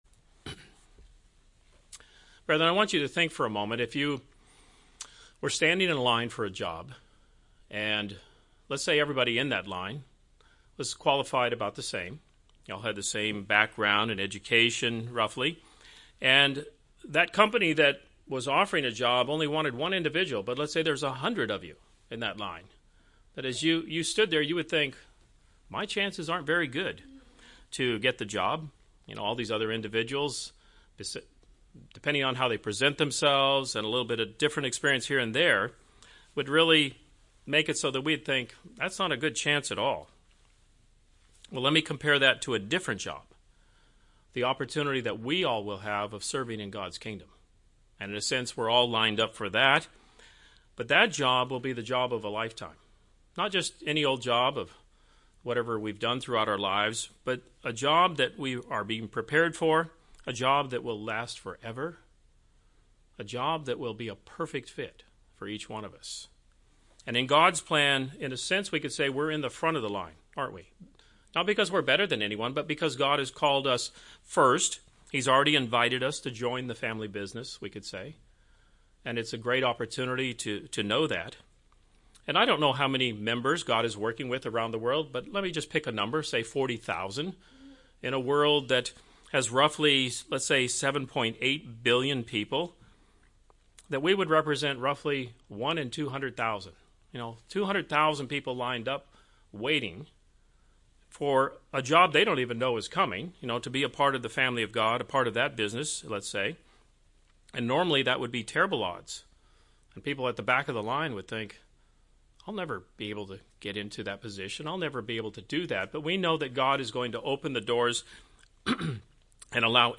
This sermon discusses several points of why a congregation is so important for the members of the body of Christ. God had a beautiful purpose in mind when He established a weekly gathering for the purpose of meeting with His children.
Given in St. Petersburg, FL